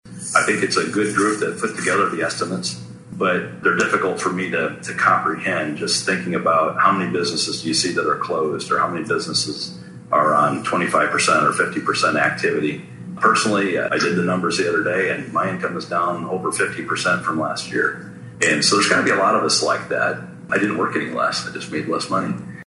The first Carroll Chamber of Commerce Legislative Forum on Saturday was filled with questions revolving around funding.